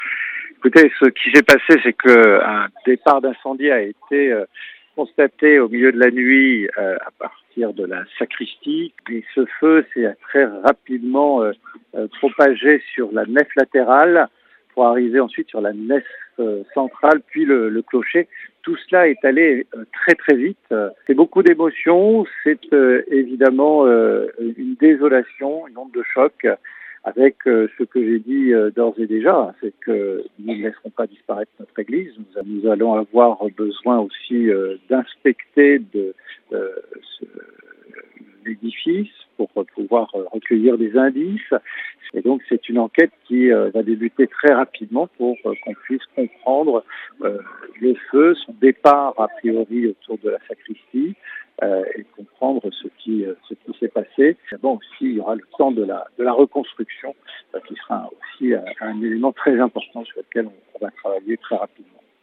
Pour François DECOSTER , Maire de ST OMER , ‘l’ Église ne disparaitra pas‘ , quand au pourquoi du sinistre , l’enquête est en cours LOGO HAUT PARLEURÉcoutez